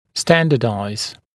[‘stændədaɪz][‘стэндэдай’з]стандартизировать, нормировать